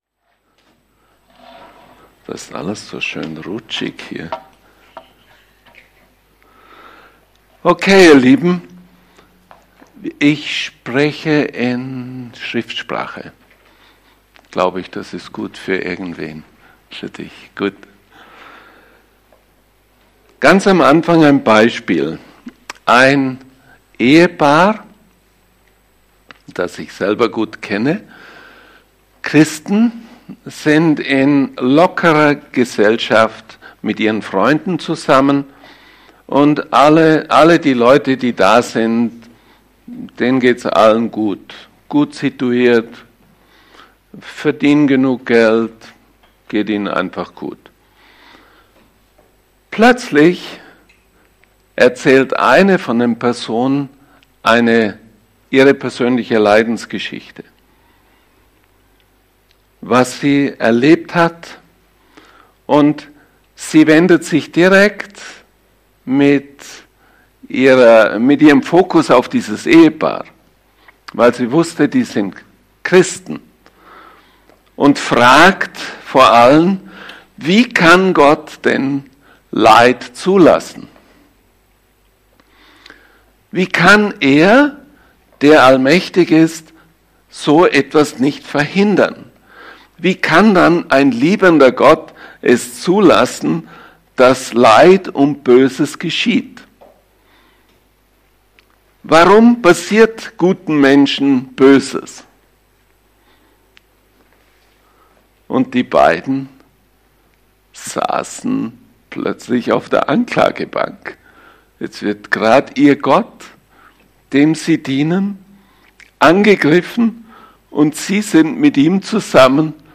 1-45 Dienstart: Sonntagmorgengottesdienst « Du hast die Wahl Gleichnisse 1